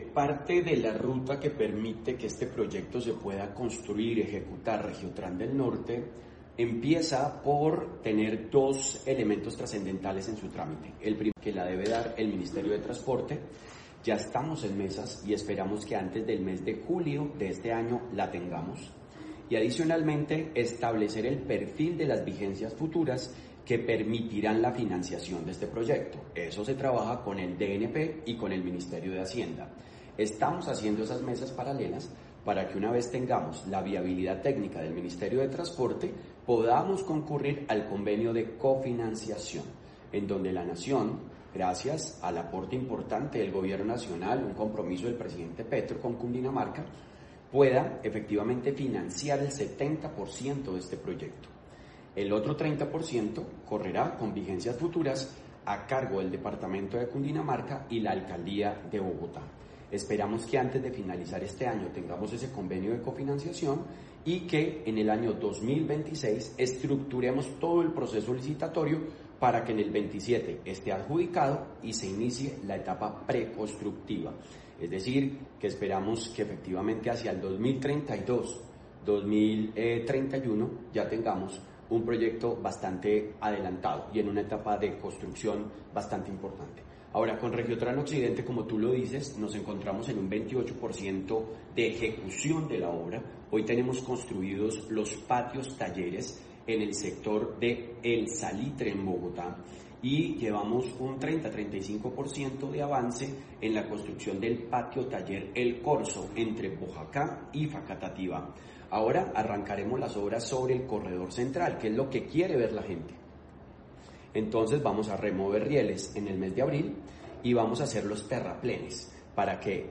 Declaraciones del gobernador Jorge Rey.